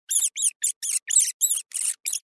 Все записи натуральные и четкие.
2. Писк мыши